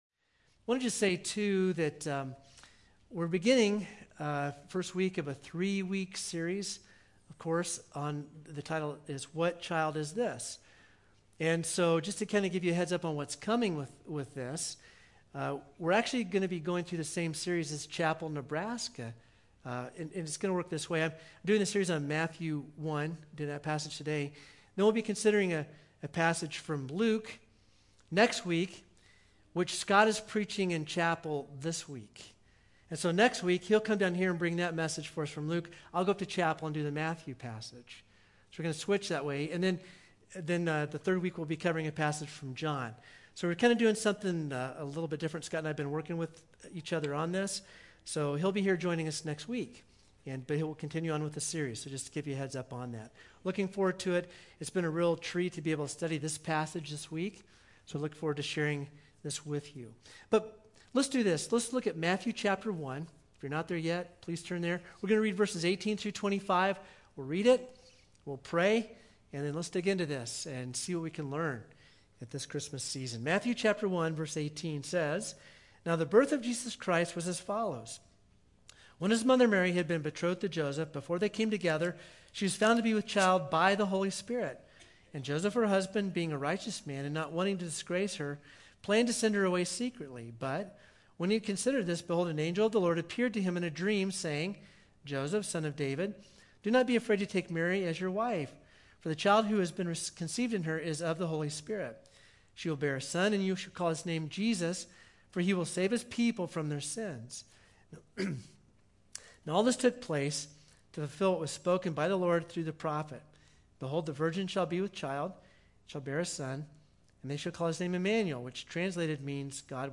Sermon-12_5-21.mp3